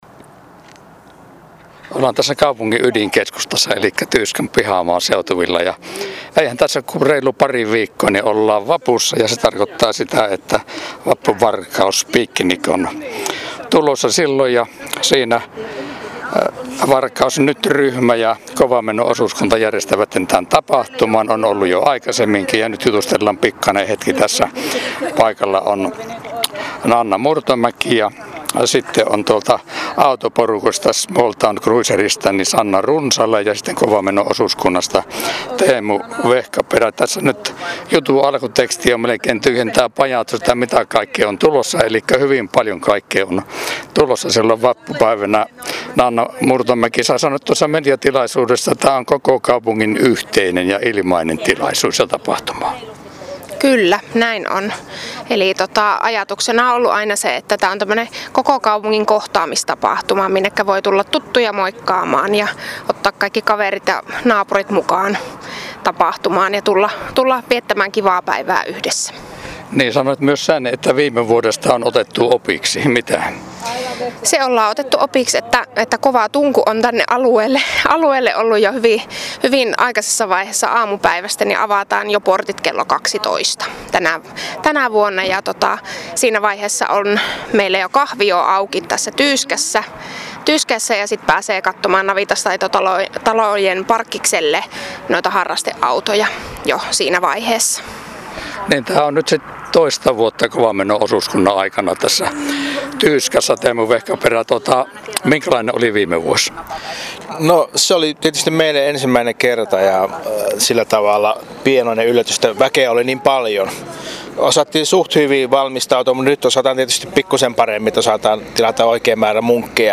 Äänihaastattelussa